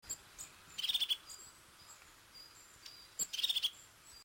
Trekroepjes Mezen
Kuifmees.mp3